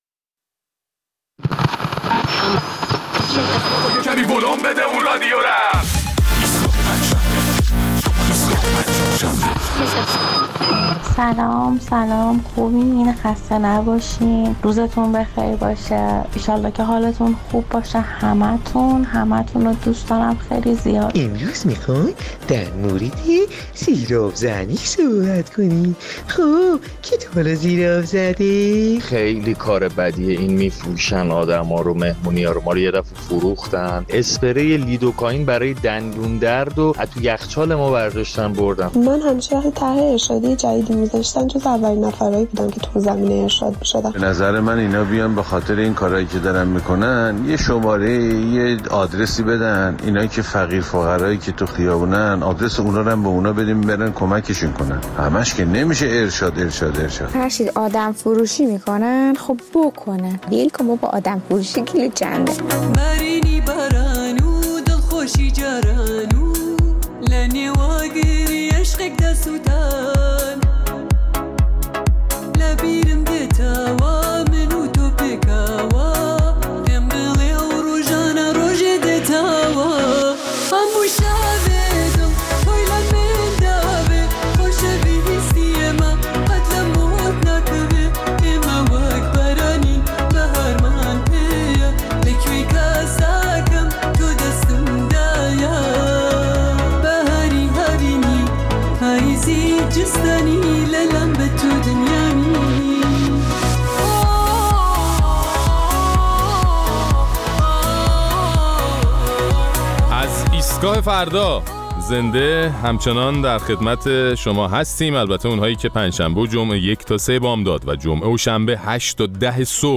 در این برنامه ادامه نظرات و تجربیات شنوندگان ایستگاه فردا را درباره فراخوان دادسرای ارشاد از مردم در زمینه گزارش دادن موارد منکراتی می‌شنویم.